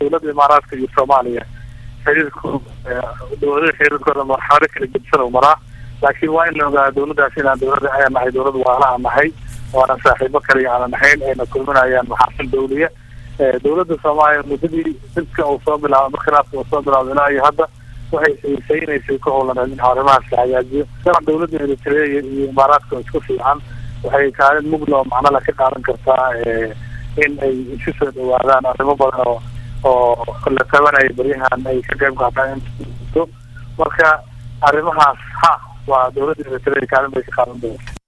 Daahir Geelle oo wareysi siinayay laanta afka Soomaaliga ee VOA ayaa sheegay in xiriirka Soomaaliya iyo Imaaraadka uu marxalado kala duwan marayo, isla markaana ay yihiin dowlad walaalo ah oo aan saaxibo kaliya aheyn.
Dhagayso Wasiirka